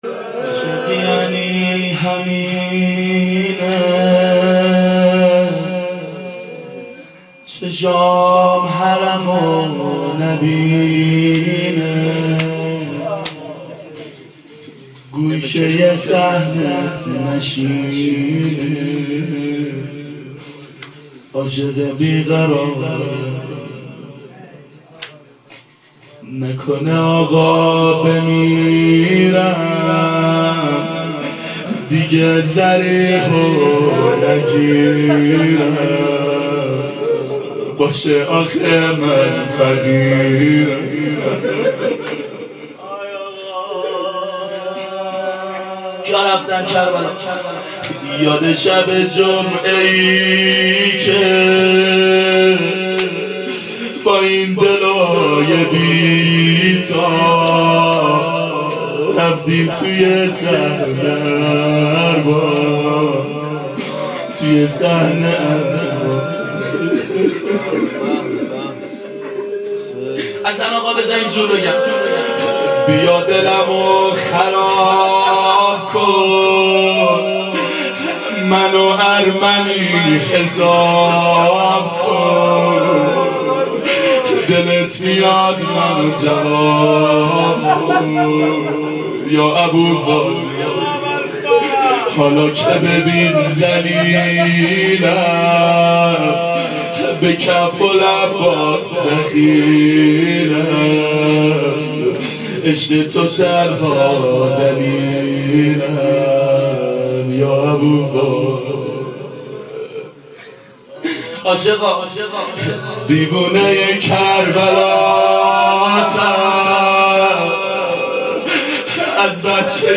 ظهر اربعین سال 1390 محفل شیفتگان حضرت رقیه سلام الله علیها